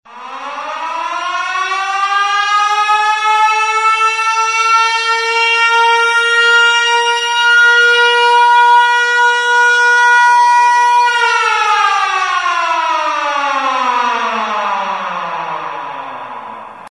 Воздушная тревога.mp3